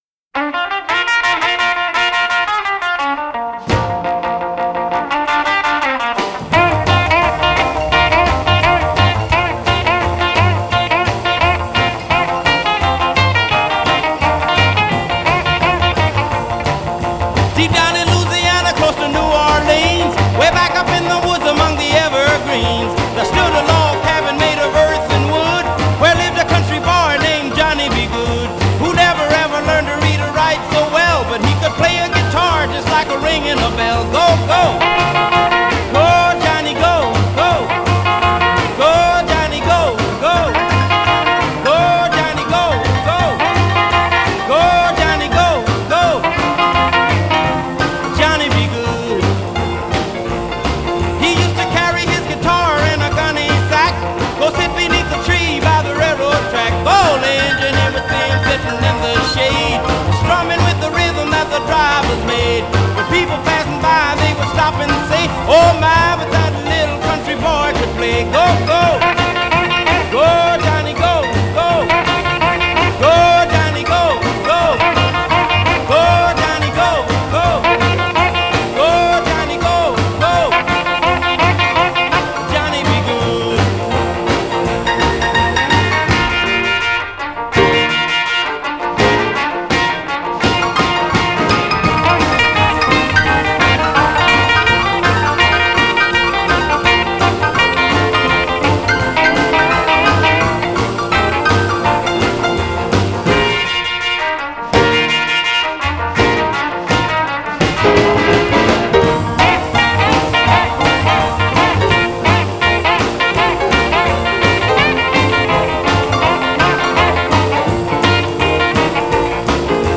Блюзы и блюзики
Жанр: blues